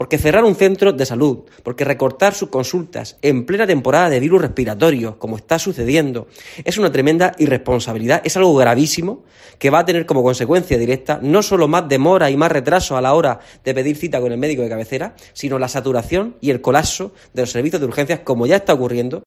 José Ángel Ponce, edil del PSOE